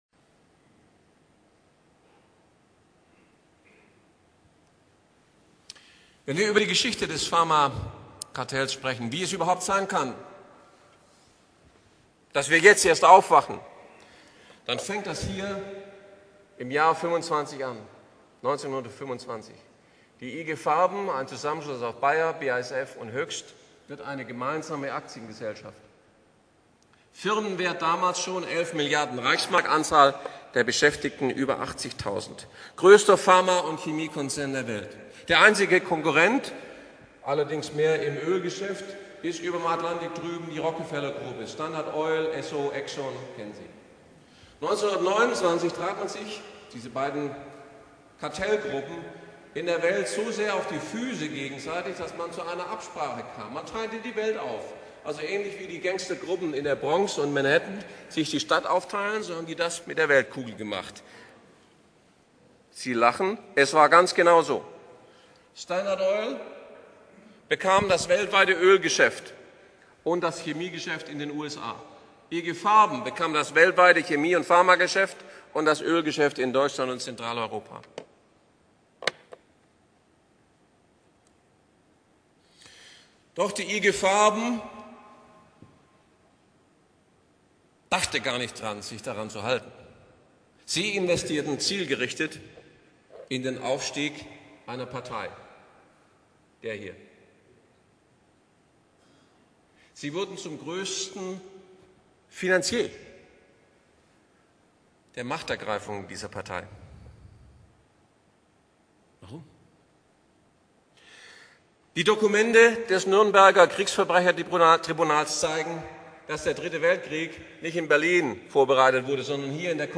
Ein Vortrag über Machenschaften des 1. und 2.Weltkrieg, Beweisstücke des Nürnberger Prozesses gegen den Chemie., Pharma- und Öl-Konzern der IG Farben (BAYER, BASF, Hoechst)